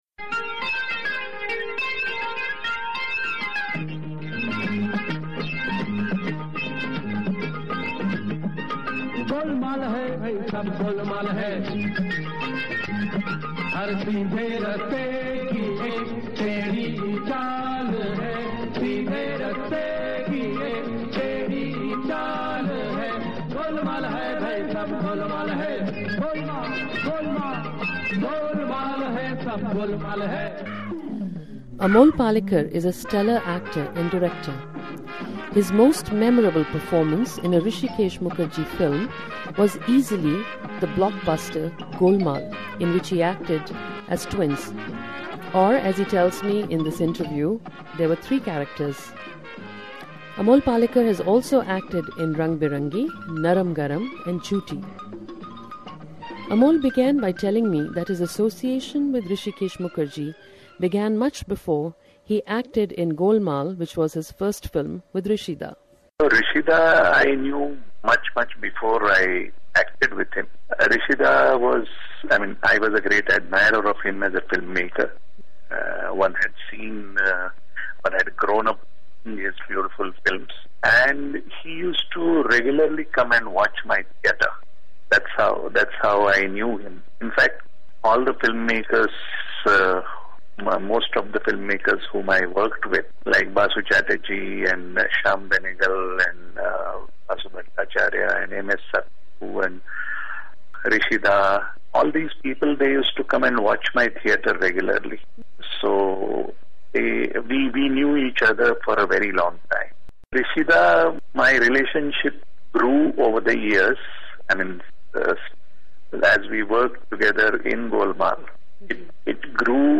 Amol Palekar in conversation